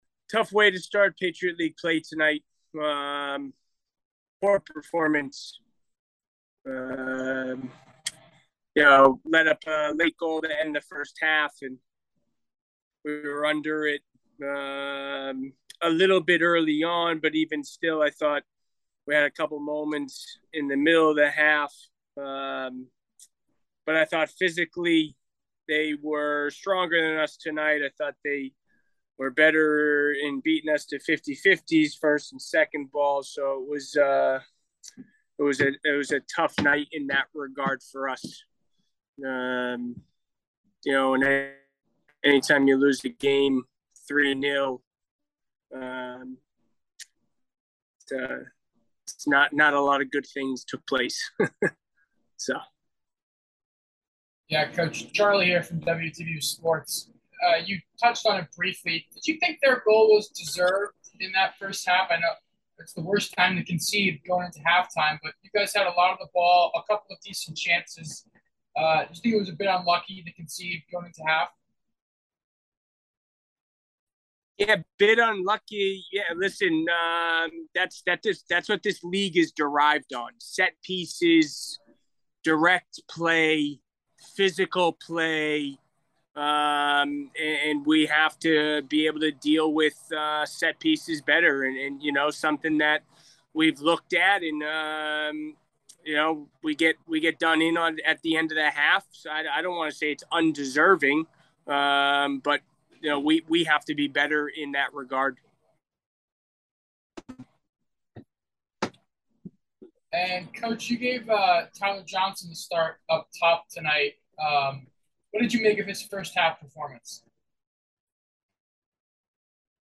Coach_Interview.mp3